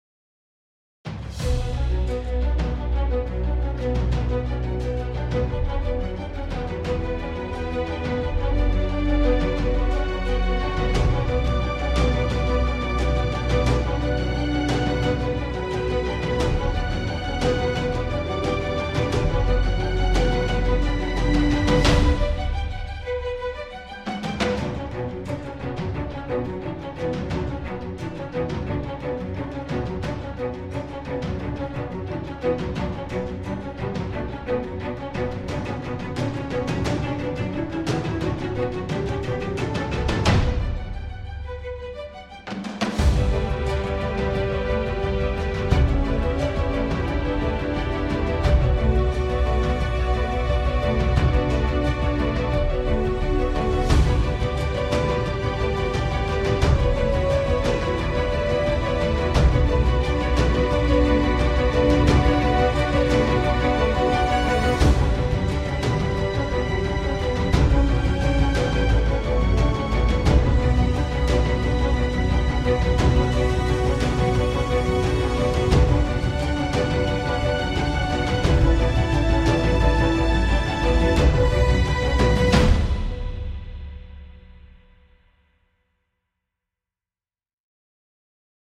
heyecan gerilim aksiyon fon müziği.